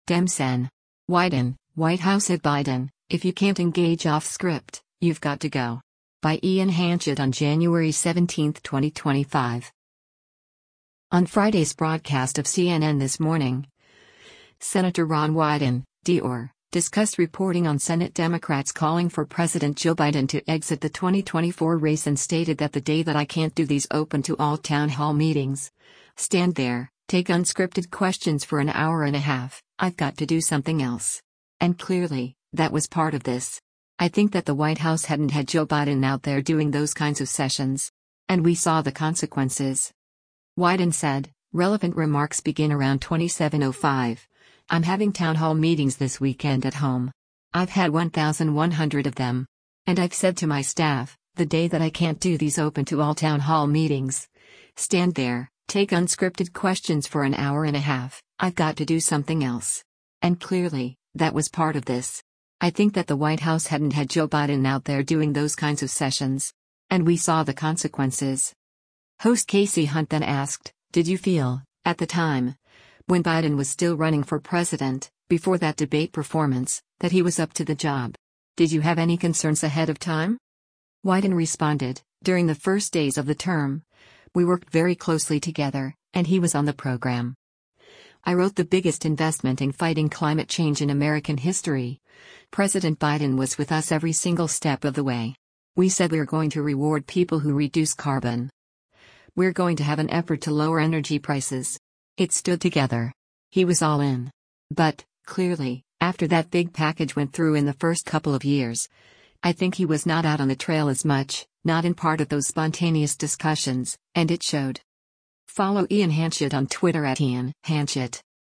Host Kasie Hunt then asked, “Did you feel, at the time, when Biden was still running for president, before that debate performance, that he was up to the job? Did you have any concerns ahead of time?”